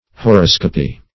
Horoscopy \Ho*ros"co*py\, n.